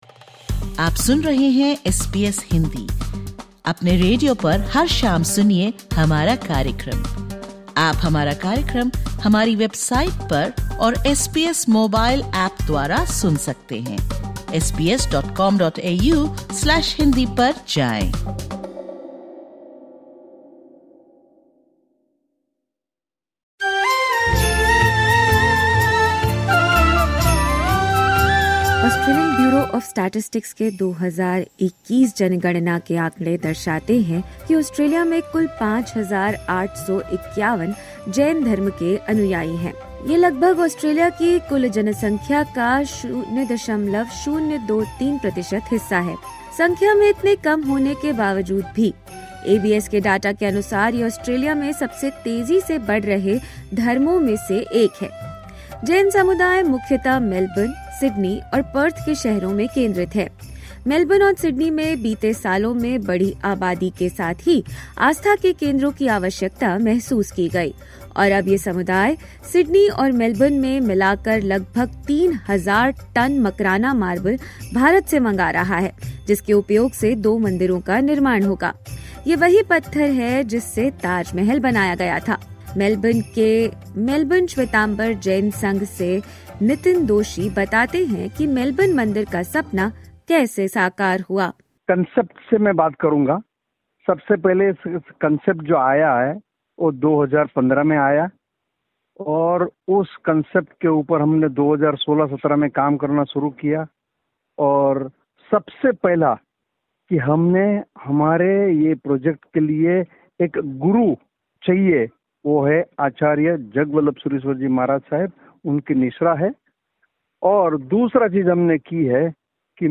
In this episode, we hear from devotees, youth scholars, and temple leaders about how a modest community, numbering almost 6,000 according to the last census, is shaping a grand legacy.